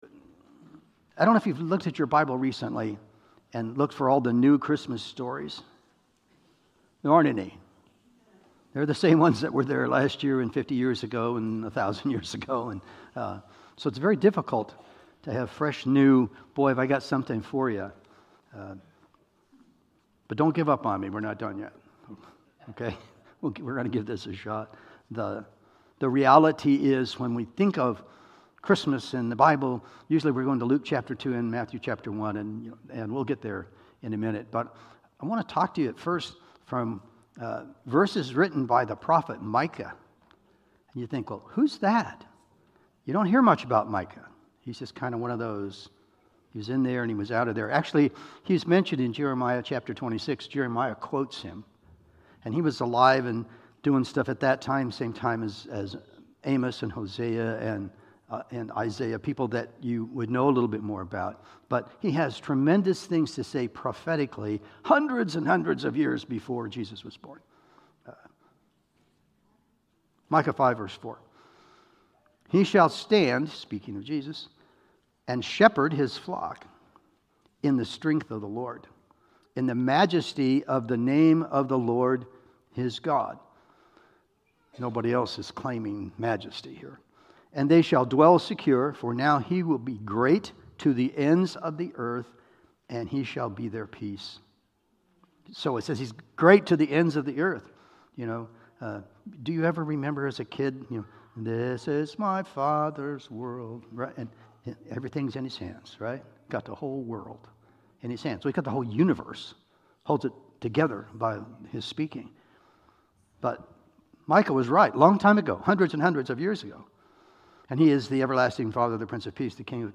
Isaiah 9:6-7 Service Type: Holiday Sermons Download Files Notes Topics